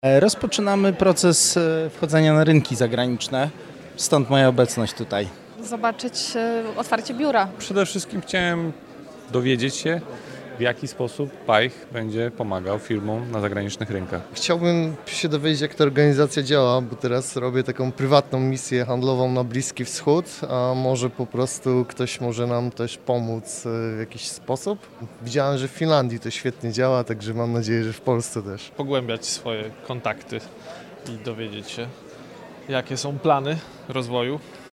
– Biorę udział w tym wydarzeniu, bo chciałabym dowiedzieć się jak poszerzyć horyzonty swojej firmy – dodaje jedna z uczestniczek konferencji.